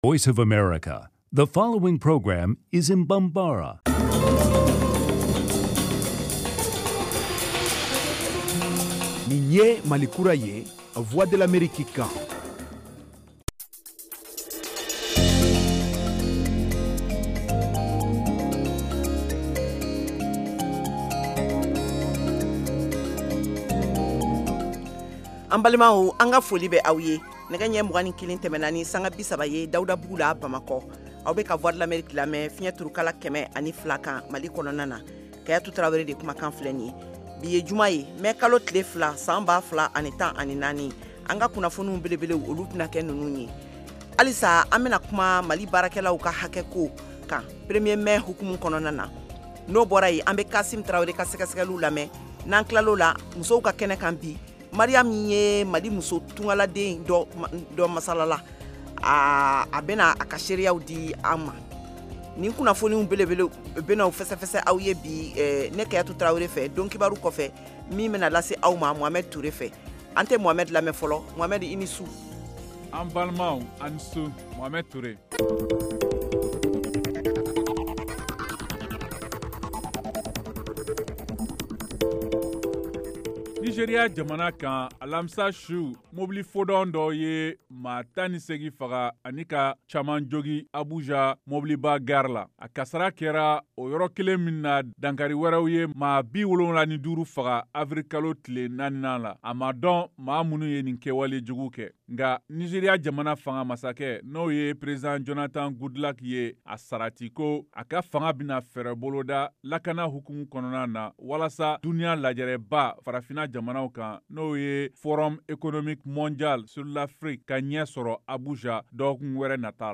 Emission quotidienne
en direct de Washington. Au menu : les nouvelles du Mali, les analyses, le sport et de l’humour.